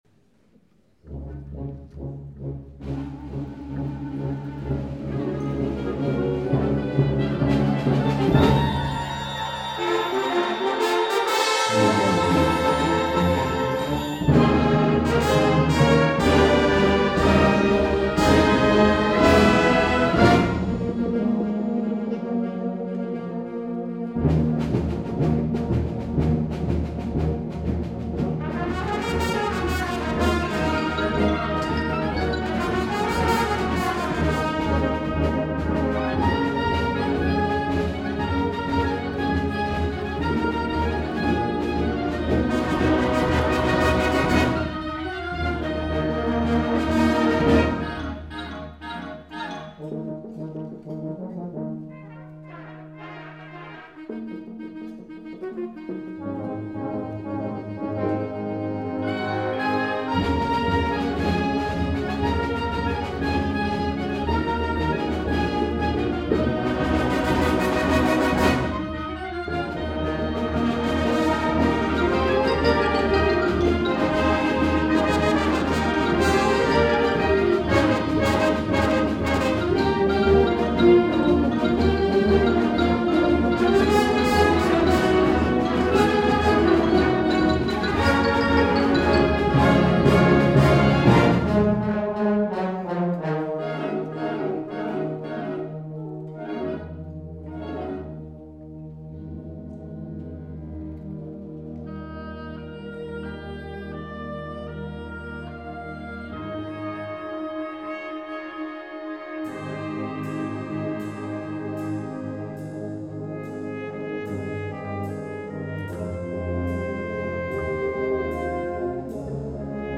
2012 Summer Concert